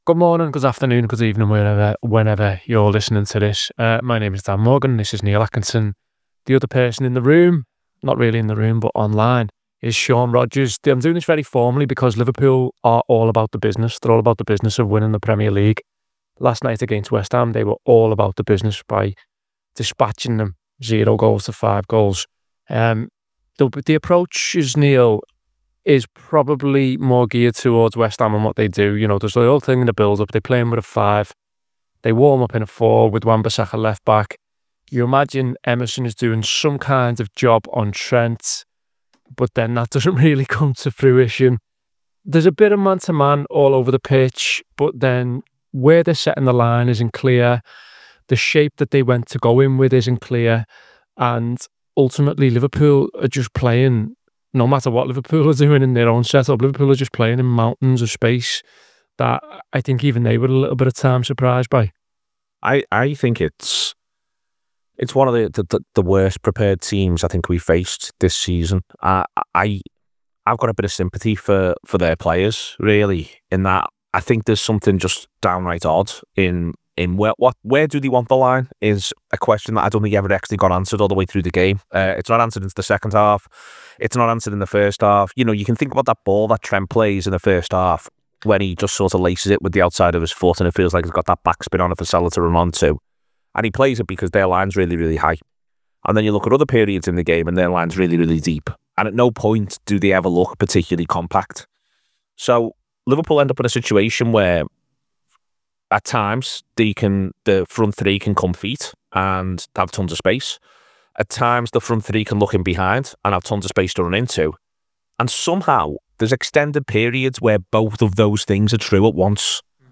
Below is a clip from the show- subscribe for more review chat around West Ham United 0 Liverpool 5…